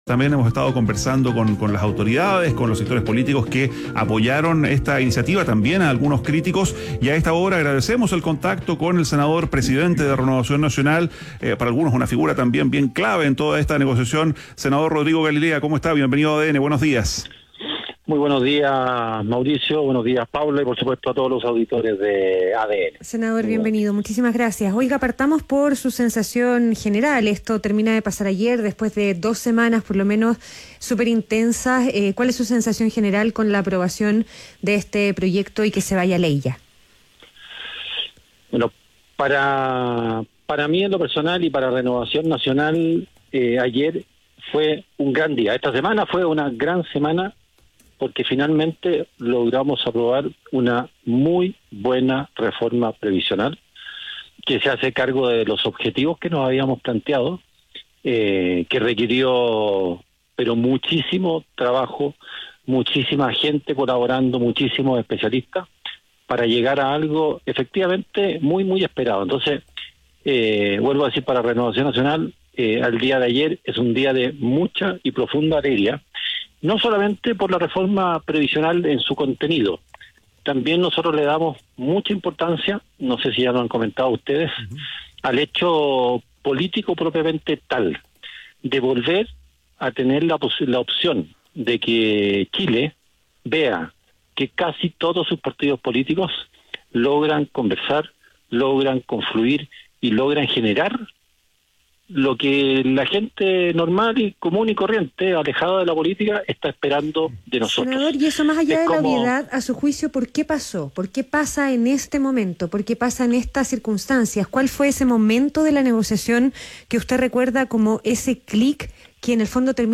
ADN Hoy - Entrevista a Rodrigo Galilea, senador y presidente de RN